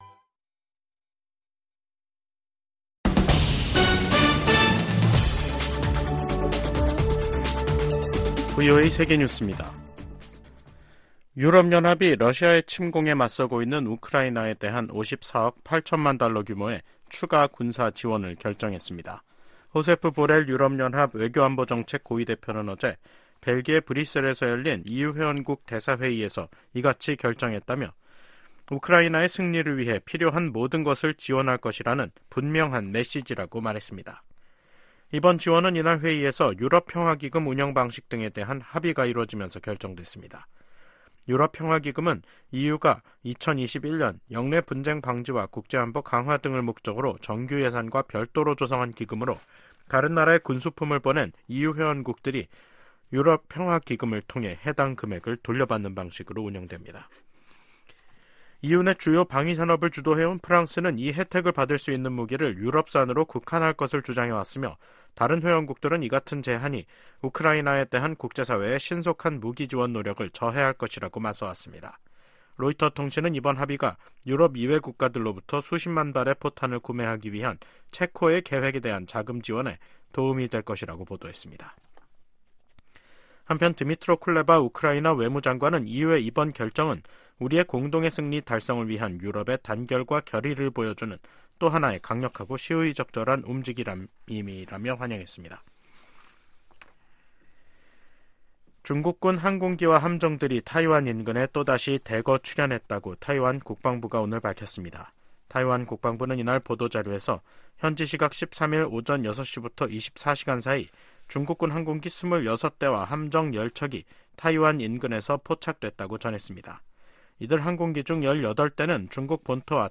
VOA 한국어 간판 뉴스 프로그램 '뉴스 투데이', 2024년 3월 14일 2부 방송입니다. 북한이 자체 핵우산을 가지고 있다는 블라디미르 푸틴 러시아 대통령의 발언에 관해, 미 국무부가 북-러 협력 강화에 우려를 나타냈습니다. 김정은 북한 국무위원장이 신형 탱크를 동원한 훈련을 현지 지도하면서 또 다시 전쟁 준비 완성을 강조했습니다. 미 의회 내 중국위원회가 북한 노동자를 고용한 중국 기업 제품 수입 즉각 중단을 행정부에 촉구했습니다.